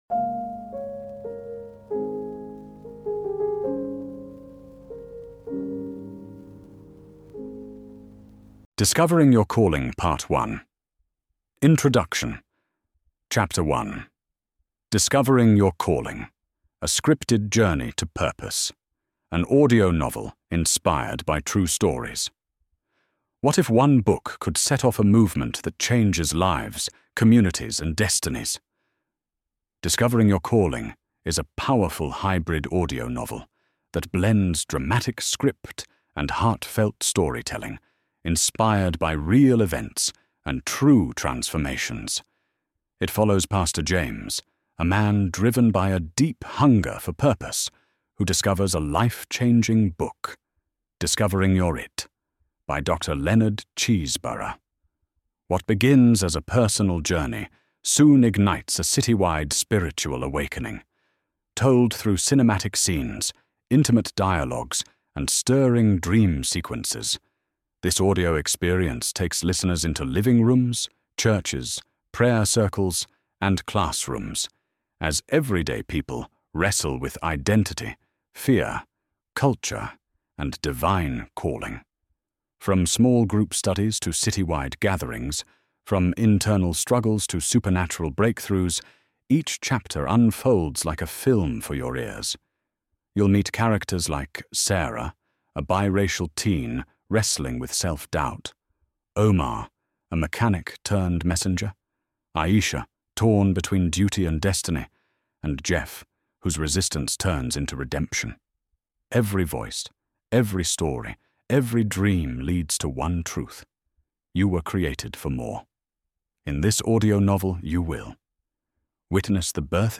Discovering Your Calling: Audio Novel playlist